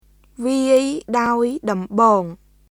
[ヴィアイ・ダオイ・ドンボーン　viˑəi daoi dɔmbɔːŋ]